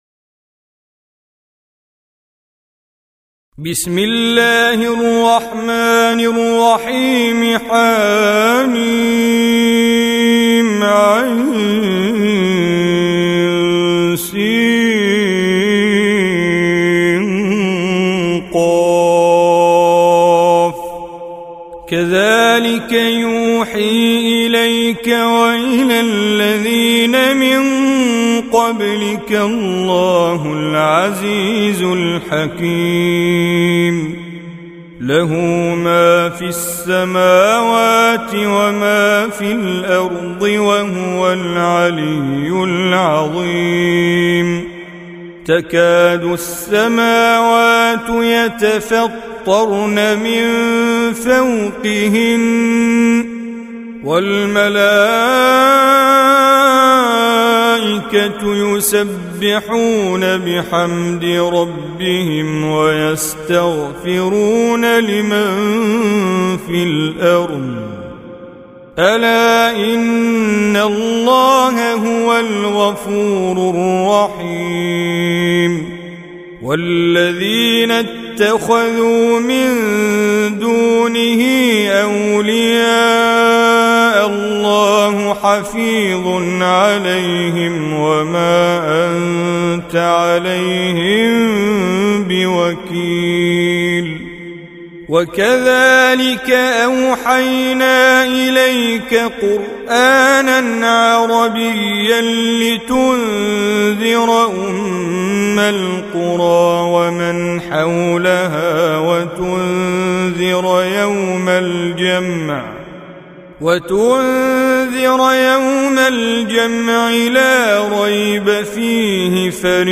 Surah Repeating تكرار السورة Download Surah حمّل السورة Reciting Mujawwadah Audio for 42. Surah Ash-Sh�ra سورة الشورى N.B *Surah Includes Al-Basmalah Reciters Sequents تتابع التلاوات Reciters Repeats تكرار التلاوات